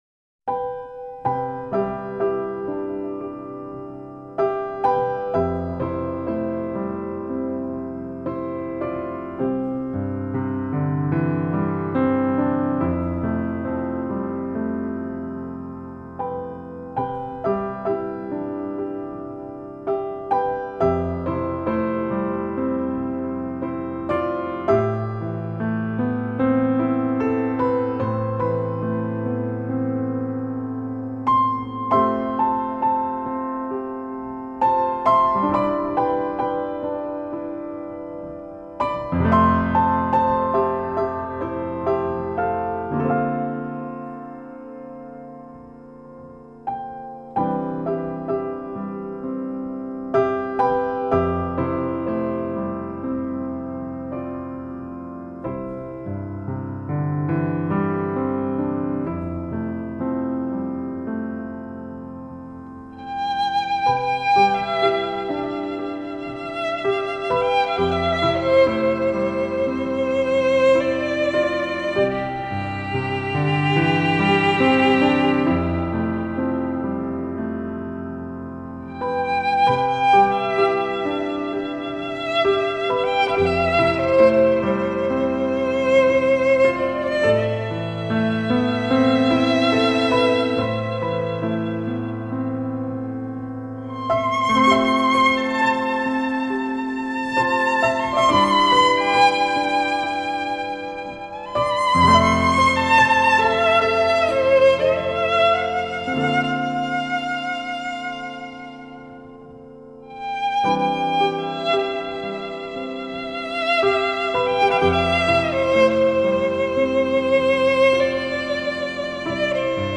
Genre: New Age